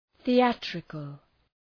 Shkrimi fonetik {ɵi:’ætrıkəl}